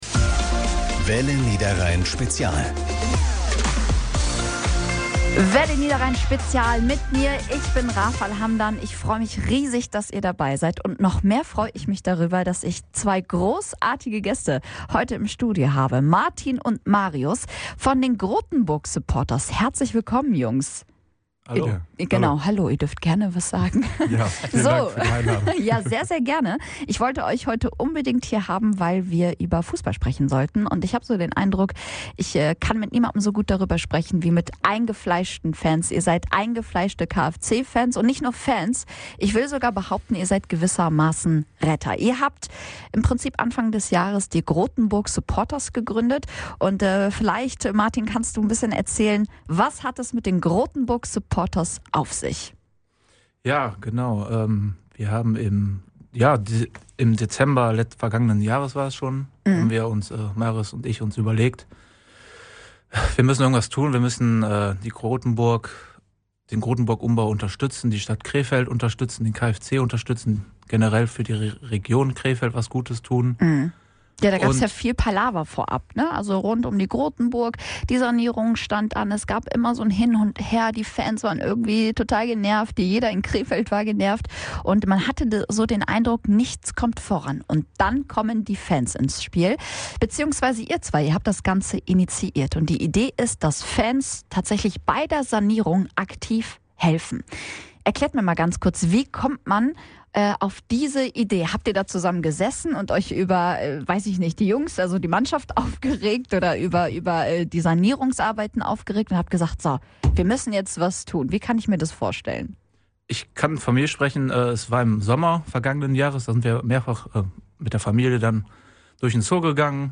_-_talk_am_sonntag_kfc.mp3